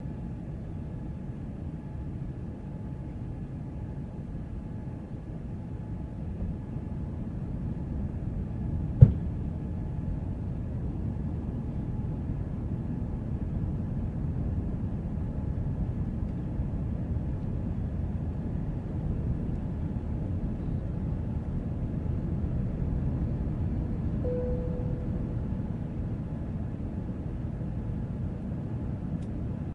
电动发动机 I
描述：发动机启动和断电的声音。
标签： 电影 电机 电时 故障 Downriser 错误 驱动器 福利 提升 设备 科学幻想小说 游戏 效果 崛起 掉电 外汇 SFX 电力 交通运输 引擎 开始 电影
声道立体声